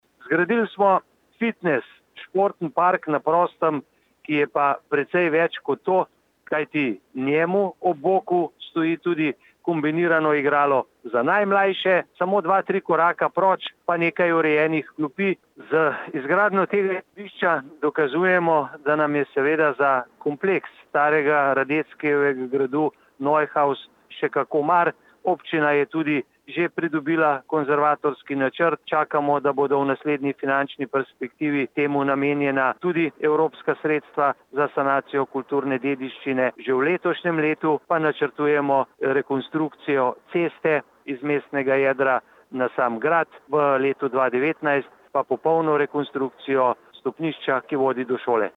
izjava_zupanobcinetrzicmag.borutsajoviconovemsportnemparku.mp3 (1,2MB)